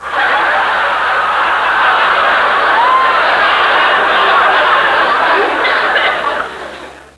click01.wav